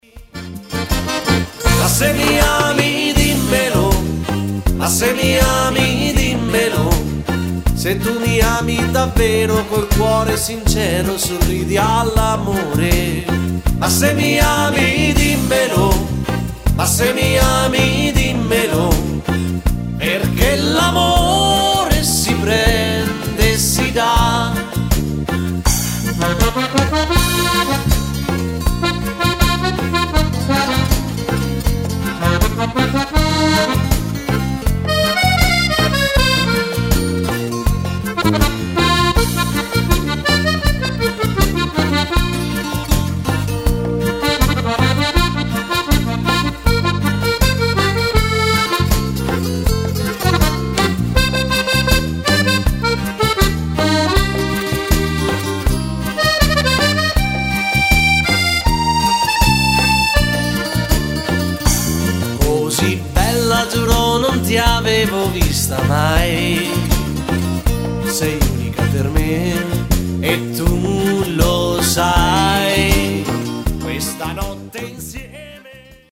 Bajon lento
Uomo